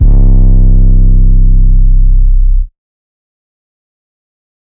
[MAMA] - 808.wav